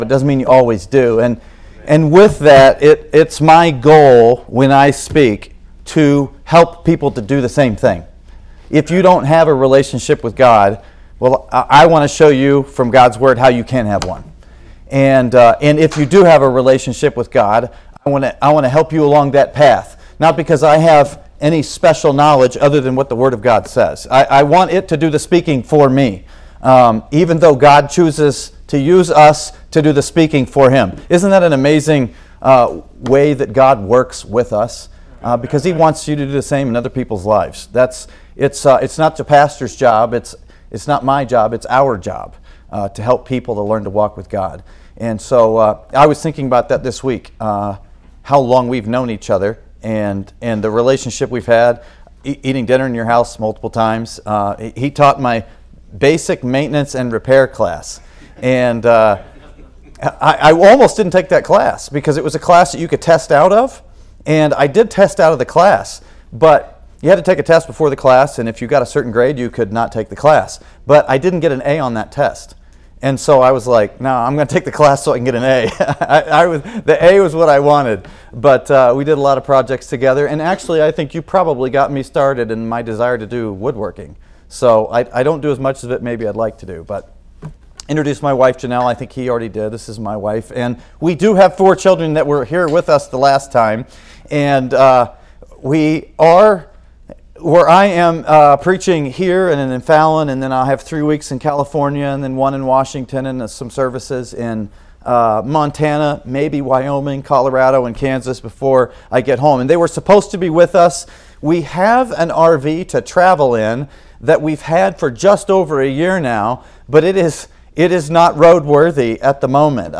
Sermons | Grace Baptist Church
Revival-Service-1.mp3